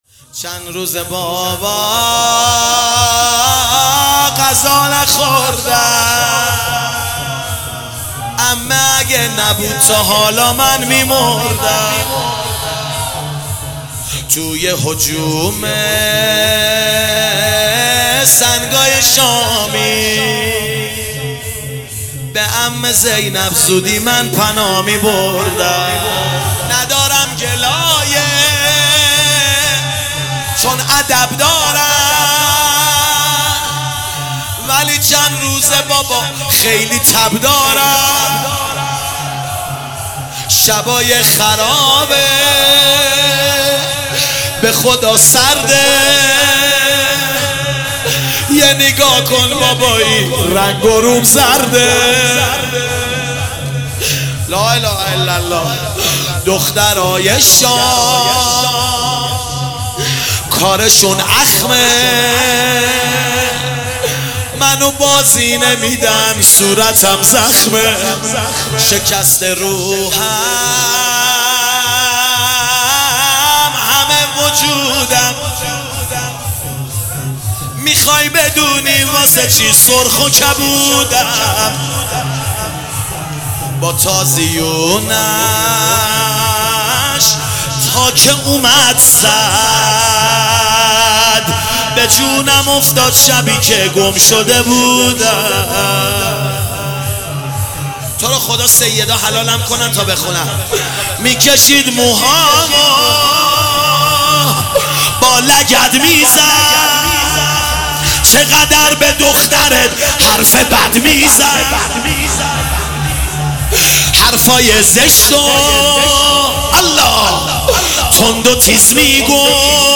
ایام فاطمیه 1441 | هیئت معظم کربلا کرمان